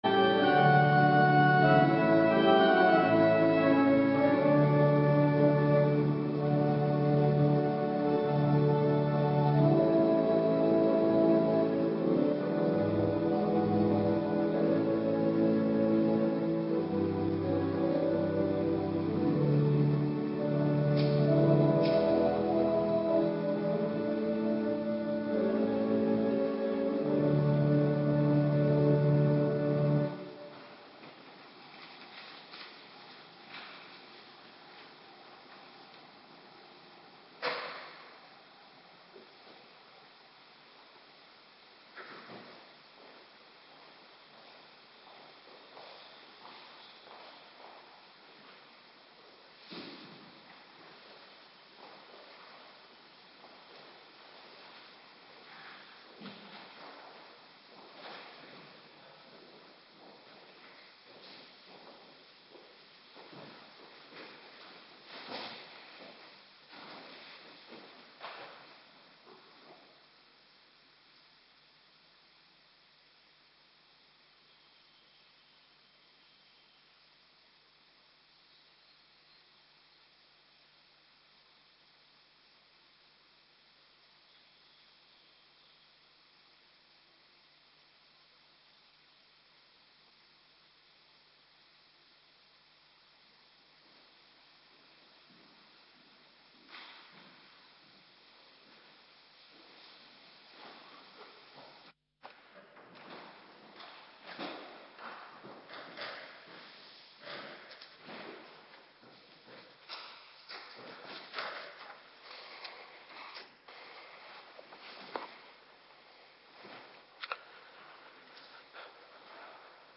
Avonddienst Eerste Paasdag
Locatie: Hervormde Gemeente Waarder